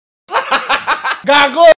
Play, download and share hahahaha gago original sound button!!!!
haha-gago-sounds-effect.mp3